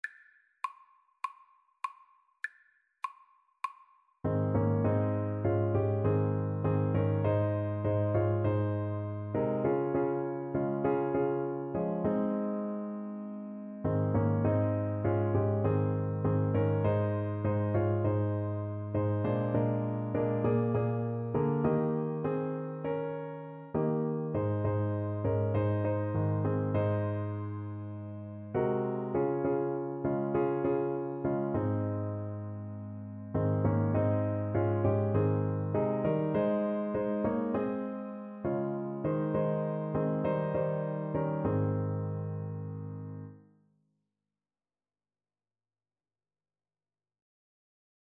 Christian
4/4 (View more 4/4 Music)